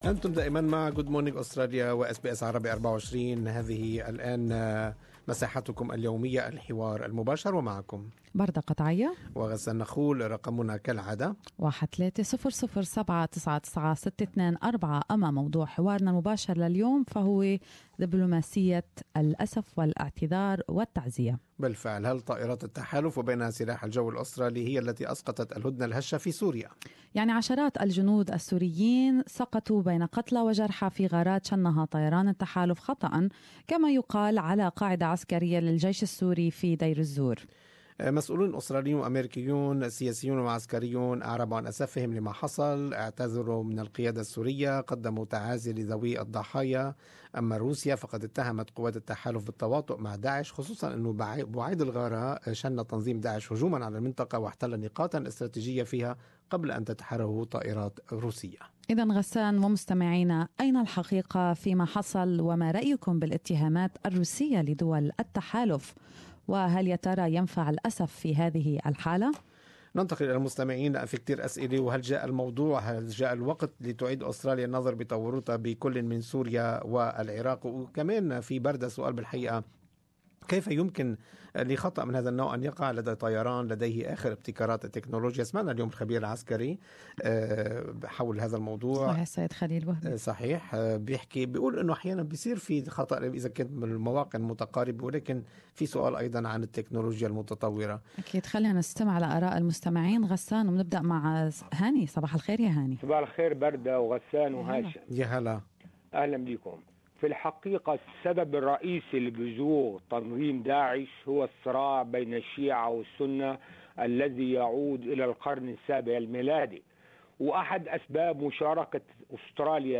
What are your thought? more in this talk back report